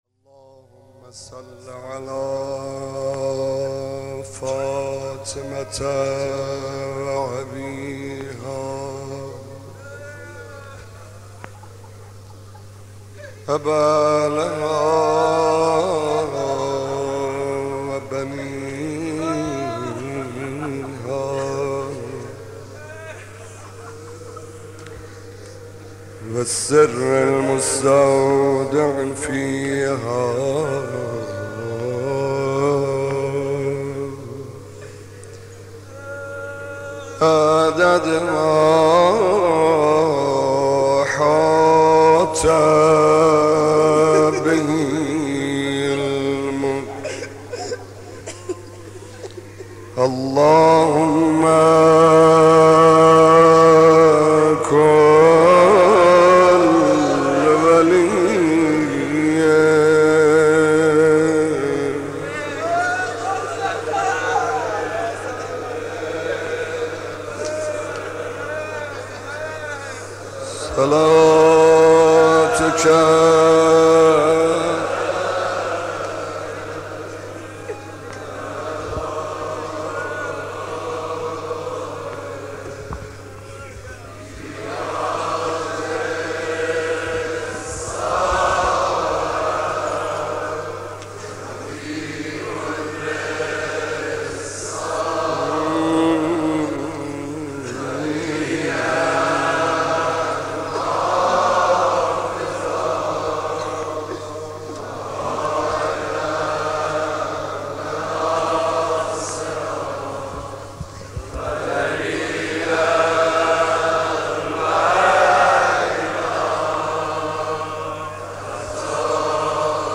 سعید حدادیان مداح
مناسبت : شب ششم محرم